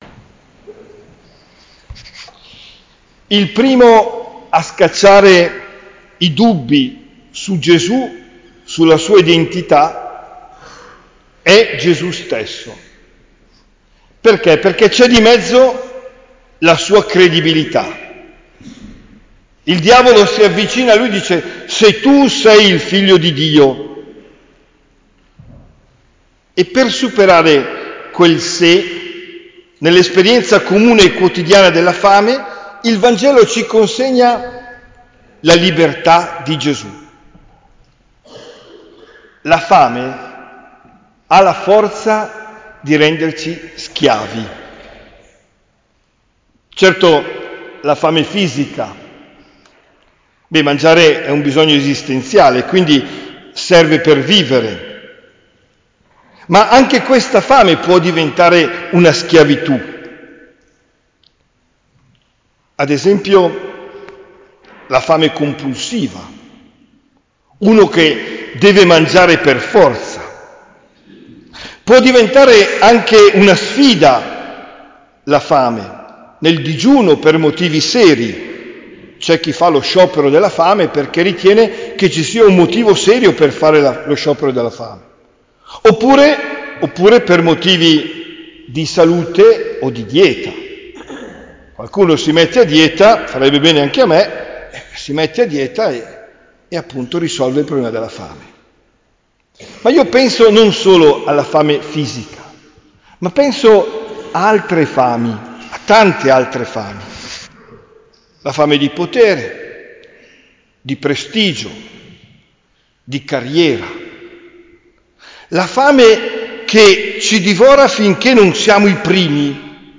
OMELIA DEL 26 FEBBRAIO 2023